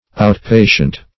Out-patient \Out"-pa`tient\, n.